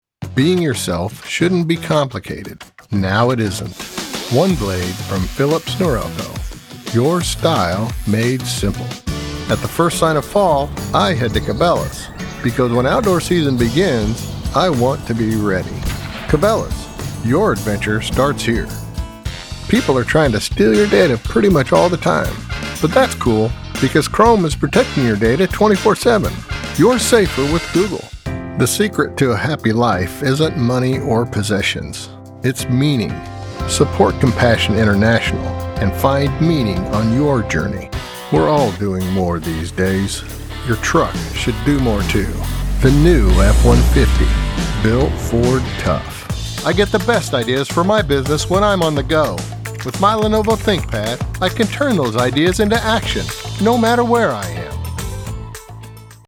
Unique rich character, warm genuine comfort
Unique. Rich. Warm. Comforting.
Commercial VO | Demo
• Professional home studio & editing, based in Lincoln Nebraska
• Audio-Technica AT2020 Cardioid Condenser Microphone